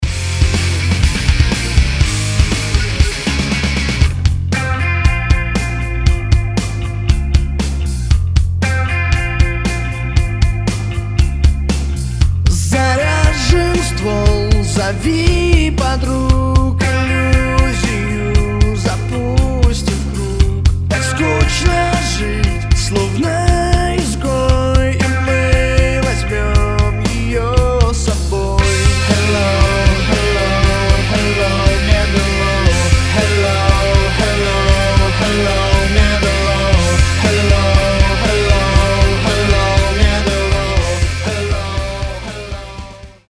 • Качество: 320, Stereo
мужской вокал
громкие
Cover
кавер версия